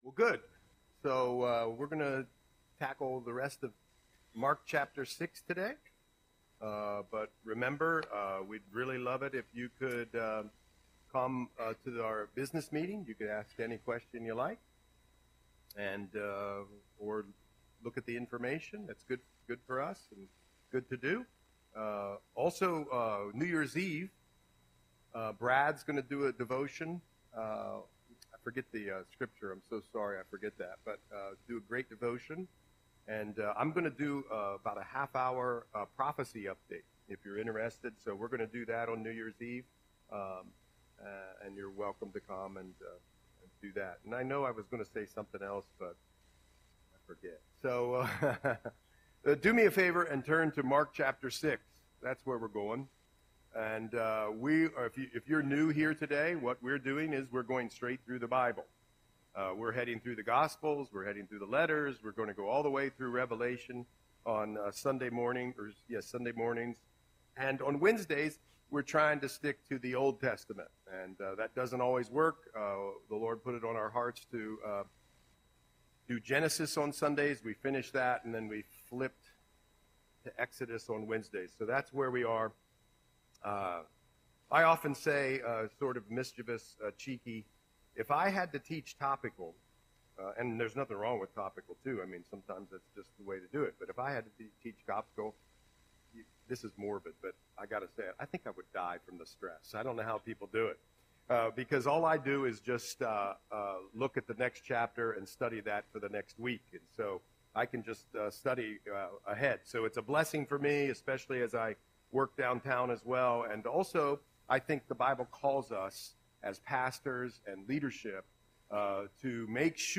Audio Sermon - December 29, 2024